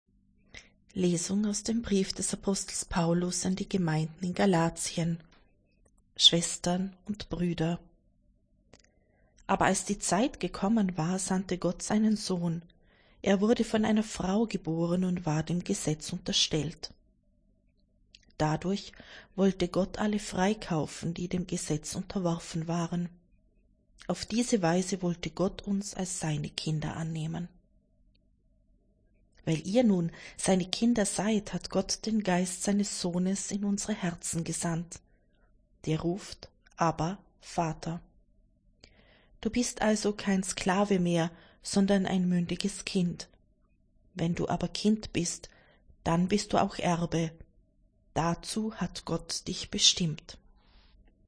Wenn Sie den Text der 2. Lesung aus dem Brief des Apostels Paulus an die Gemeinden in Galátien anhören möchten:
c-Neujahr-2.-Lesung-1.1.25.mp3